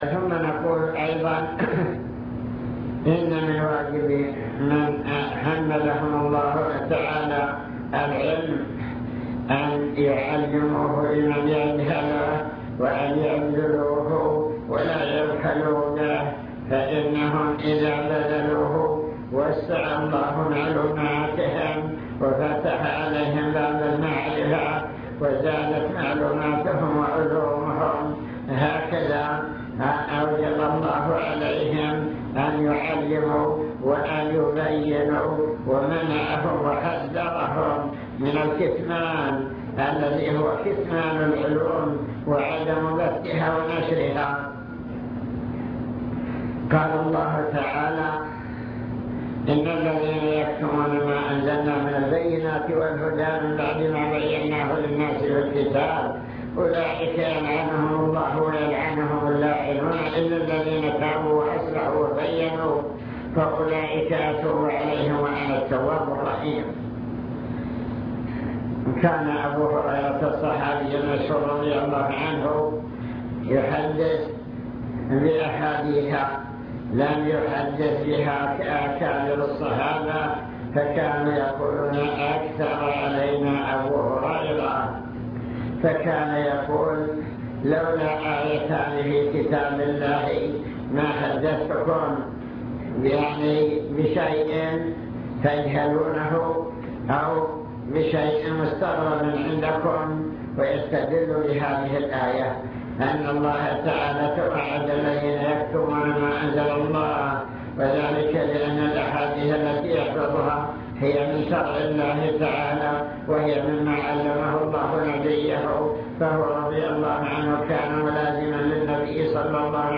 المكتبة الصوتية  تسجيلات - لقاءات  لقاء إدارة التعليم